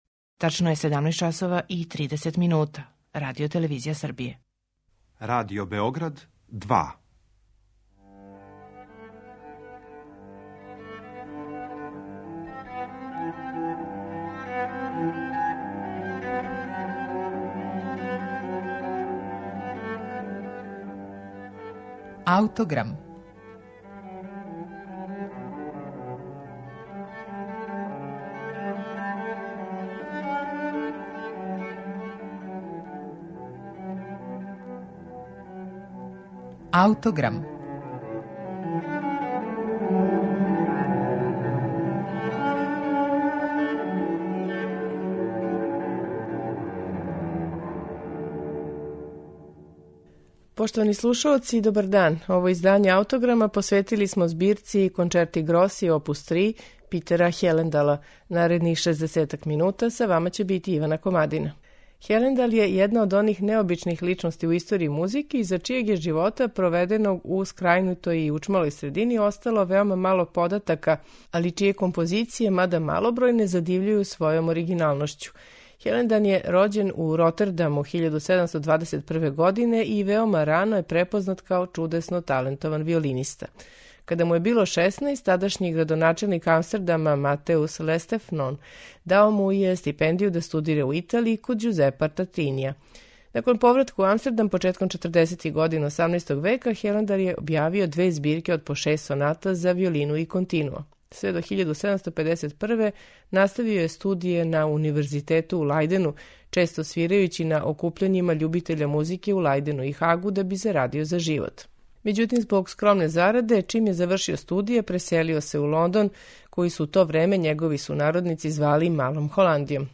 на оригиналним инструментима